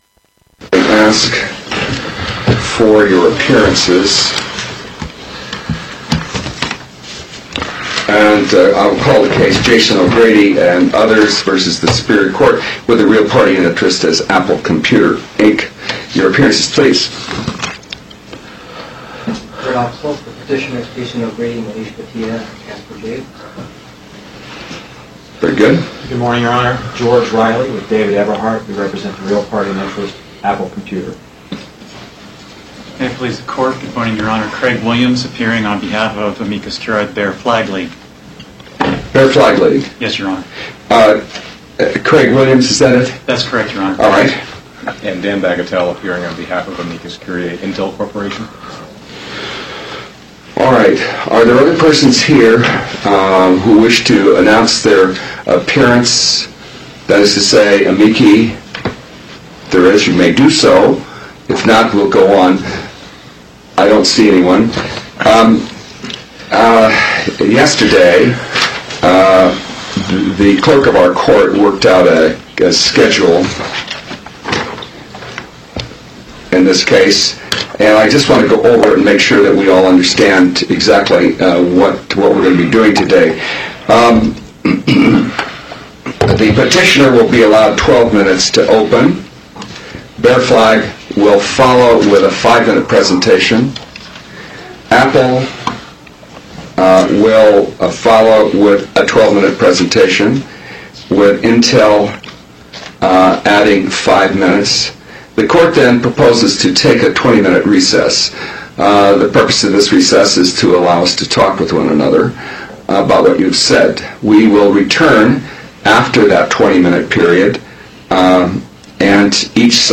apple-v-does-appellate-argument-64k.mp3